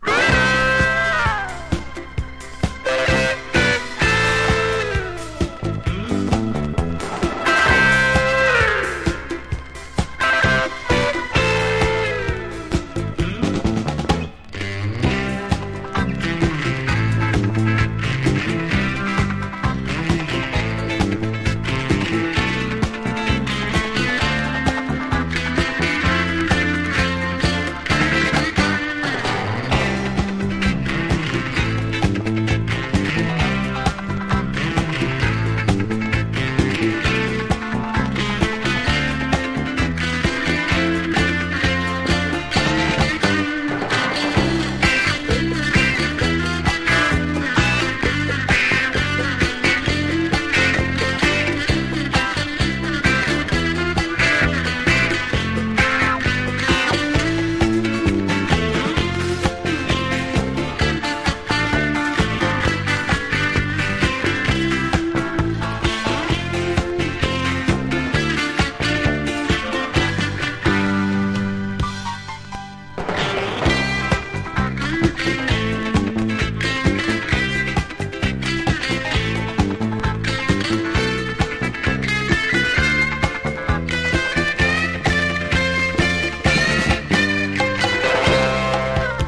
和モノ・ジャズファンクの定番
ポピュラー# SOUNDTRACK / MONDO